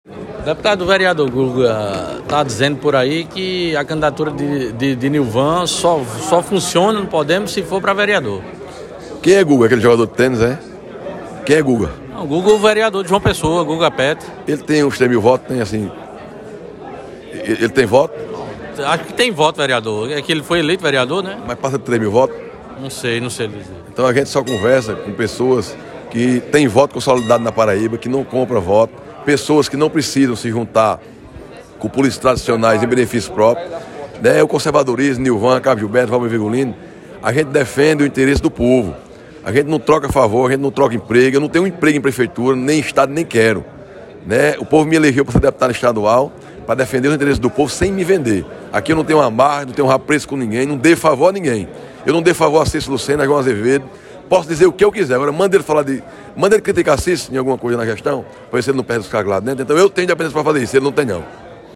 Abaixo a fala do deputado estadual Wallber Virgolino.